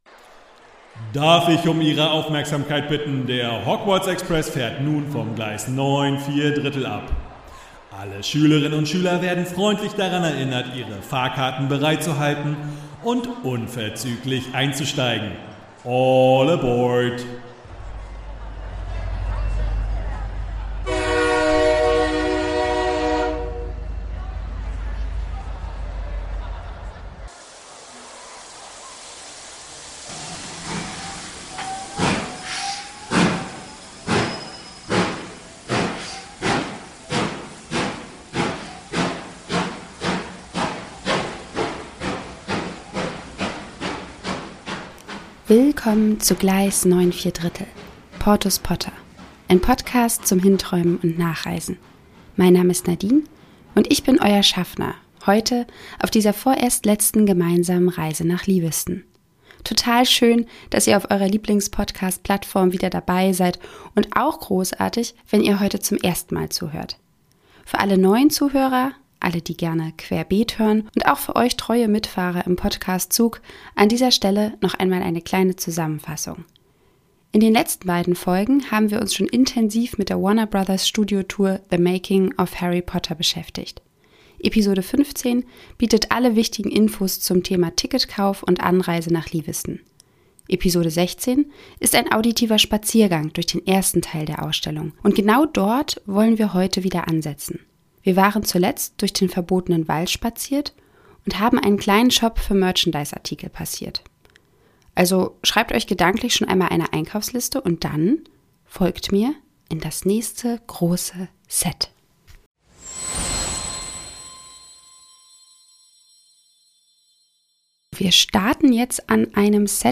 Wir machen einen auditiven Spaziergang durch den zweiten Teil der Dauerausstellung und treffen dabei u.a. auf die Winkelgasse und das wunderschöne Modell von Schloss Hogwarts, welches einen ganzen Raum füllt!